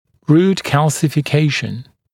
[ruːt ˌkælsɪfɪ’keɪʃn][ру:т ˌкэлсифи’кейшн]минерализация корня, формирование корня